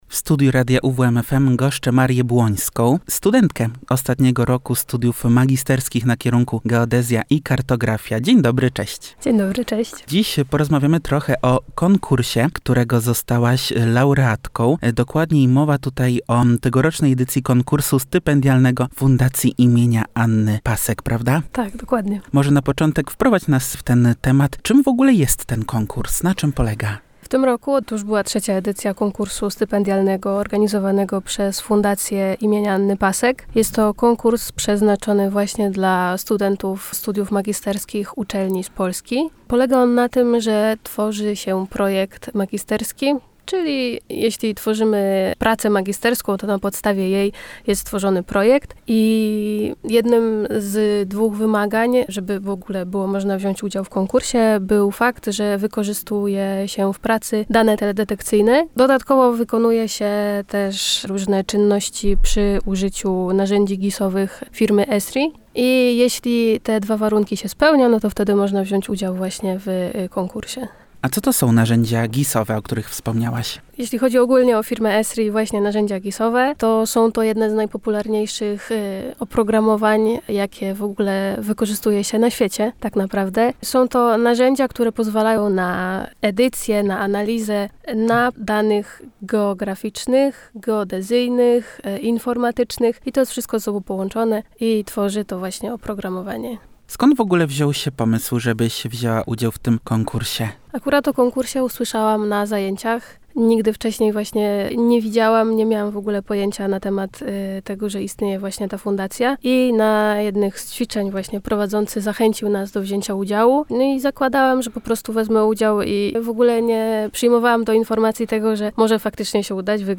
– podkreśliła w studiu Radia UWM FM studentka.